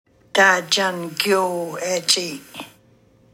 YNLC interactive Audio Lesson , Big Salmon dialect